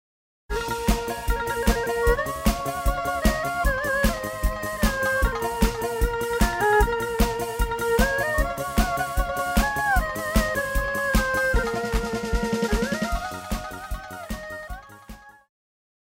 爵士
套鼓(架子鼓)
乐团
演奏曲
世界音乐
独奏与伴奏
有主奏
有节拍器